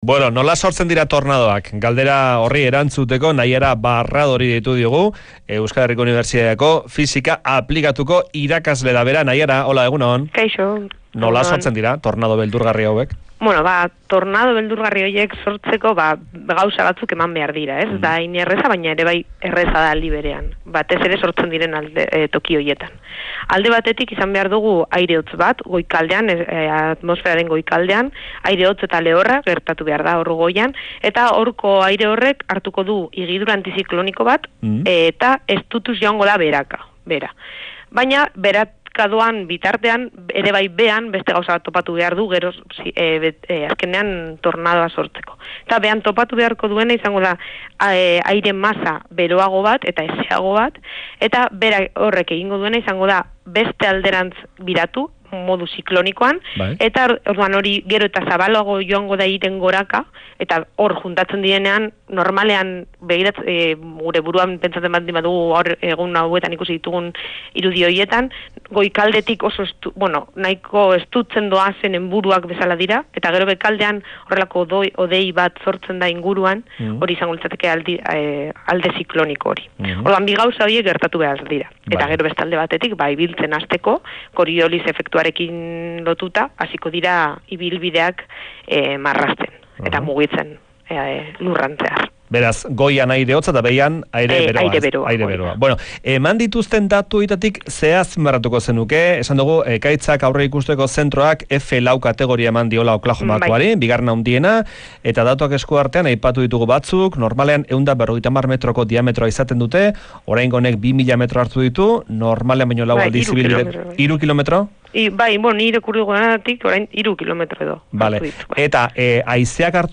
EHUko fisika aplikatuko irakasleari elkarrizketa | Faktoria